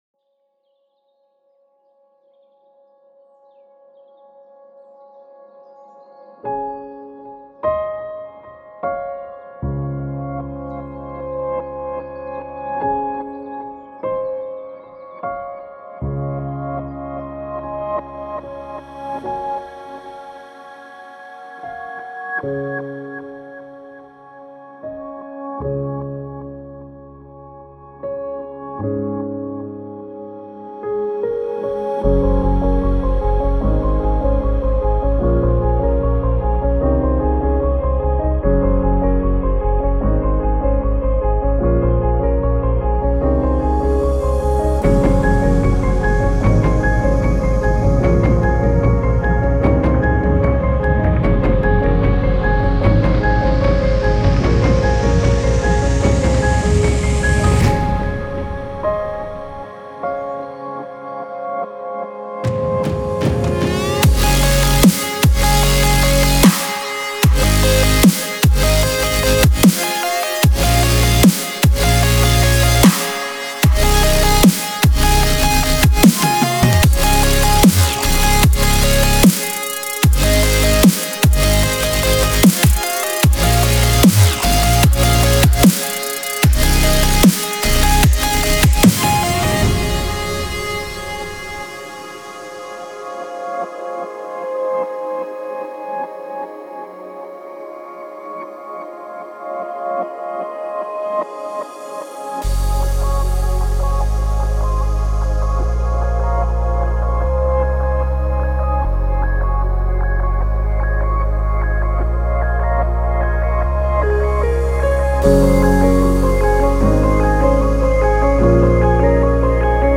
атмосферная и мелодичная композиция в жанре инди-поп